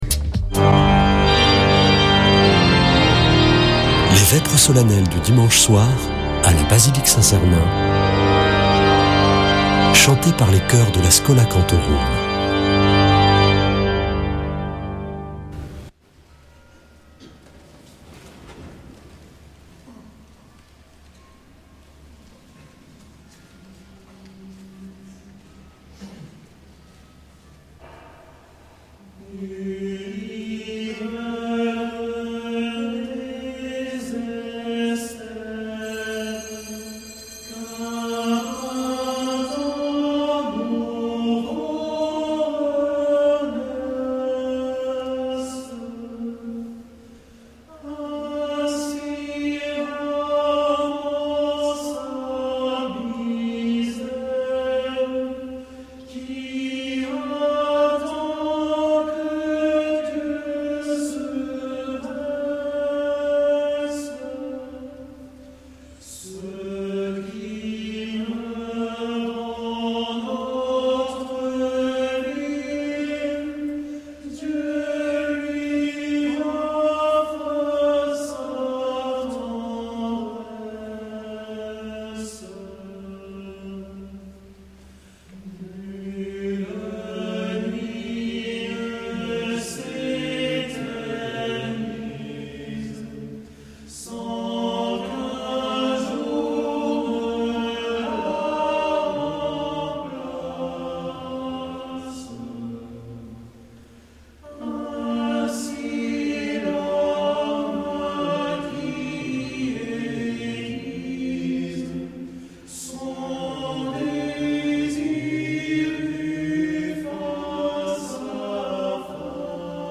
Vêpres de Saint Sernin du 22 févr.